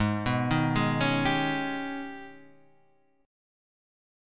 AbmM11 Chord